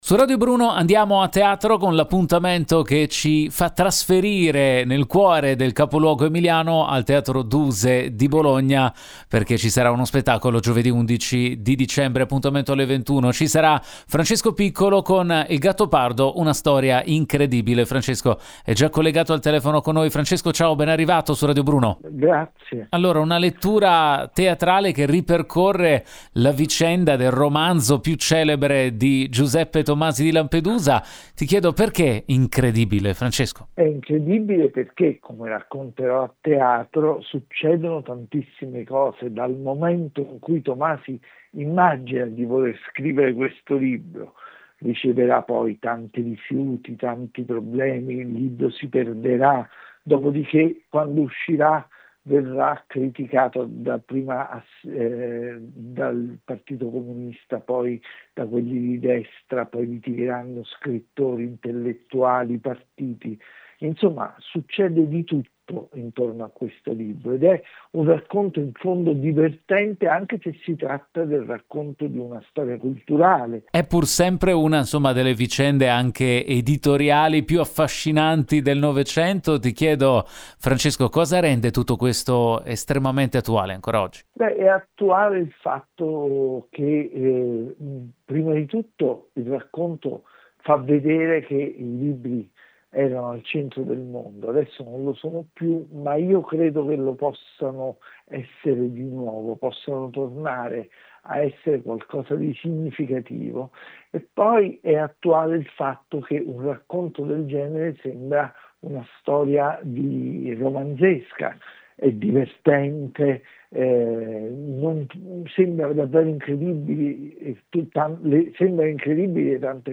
Sentiamo Piccolo, intervistato